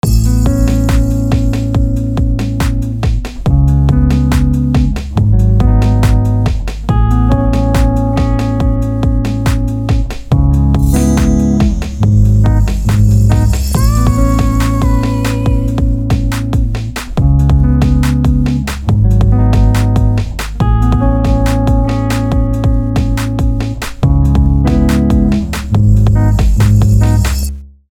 Schritt 6 – SFX
Um einen ausgefalleneren Sound zu gestalten, benutzen wir in unserem Beispiel ein Percussion-Samples als SFX. Wir setzen es auf den Taktanfang und zusätzlich lassen wir es als einleitenden Effekt rückwärts laufen.